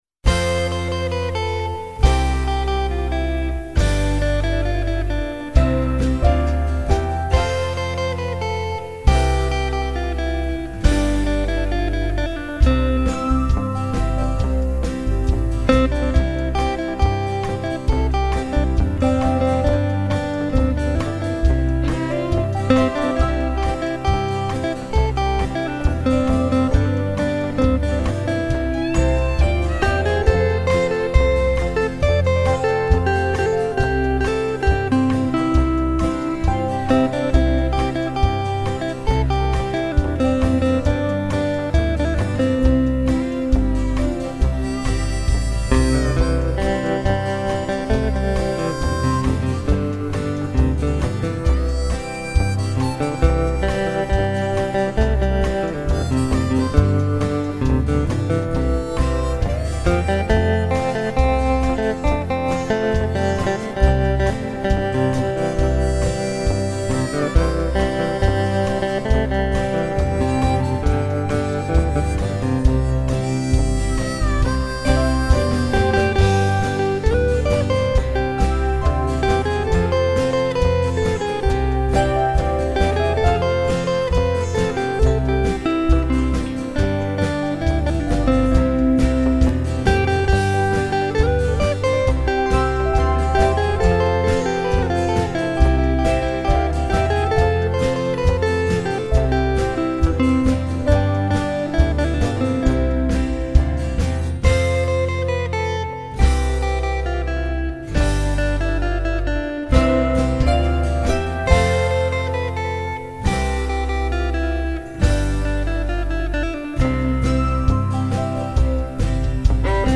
An instrumental